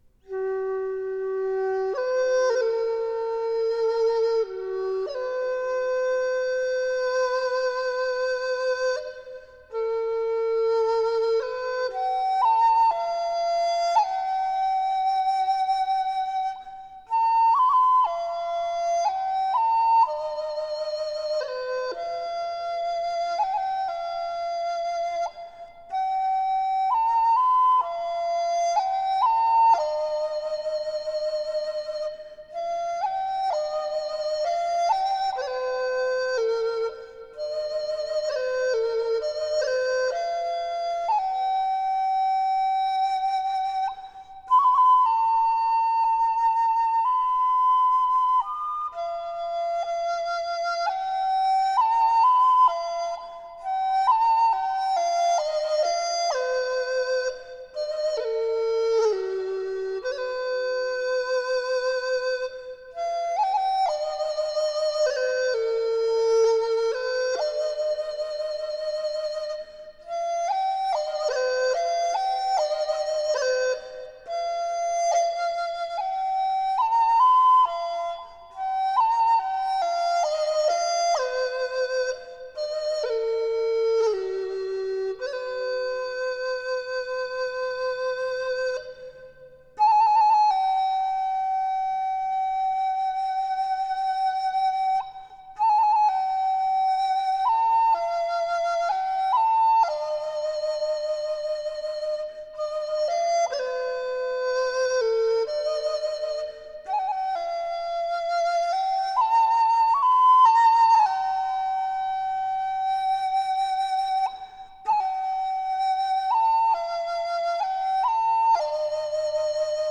Китайская музыка Медитативная музыка Флейта